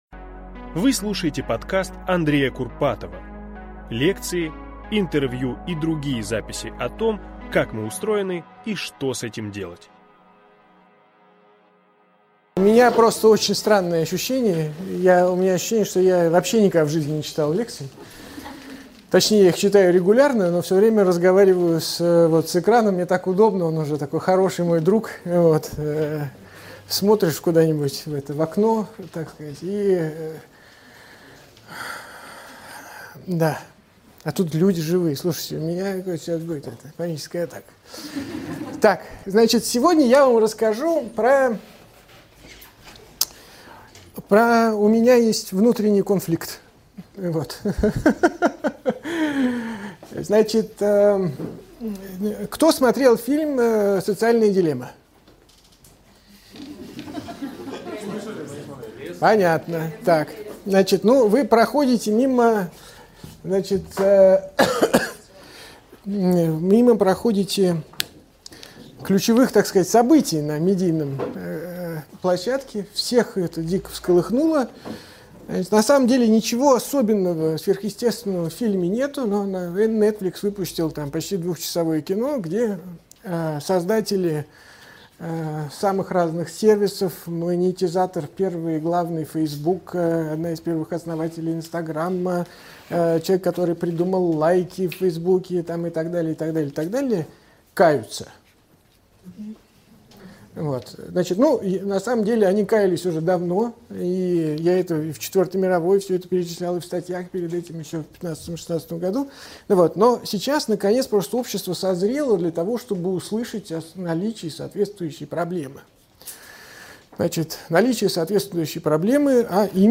Вы слушаете запись лекции для курса «Красная таблетка» от 16.10.20 Как избавиться от цифровой зависимости? Почему другие люди перестают быть интересны нам?